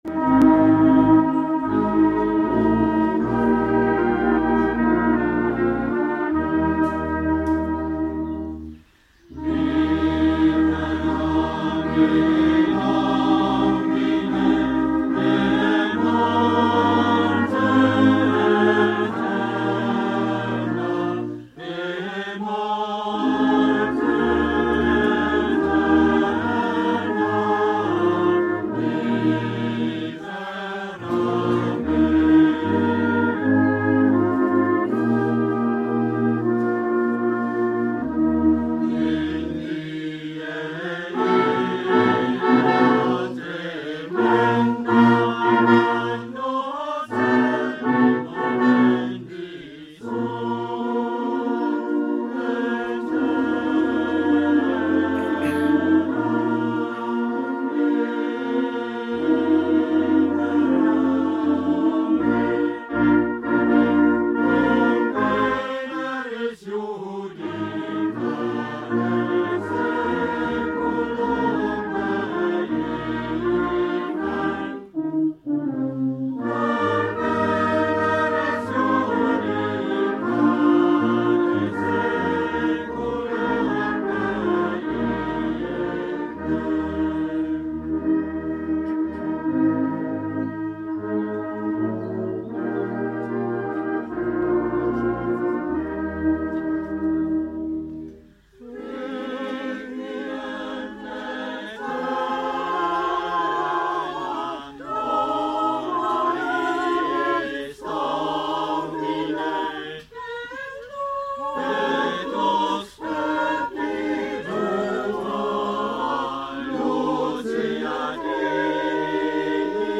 Ein bersonderer Moment bei der Gedenkfeier im Buchenwald ist das Libera, das im Soldatenfriedhof unterhalb der Buchenwaldkapelle vor dem Kriegerdenkmal gesungen wird.
Libera von 2019 (als MP3 mit Altenmarkter Kirchenchor)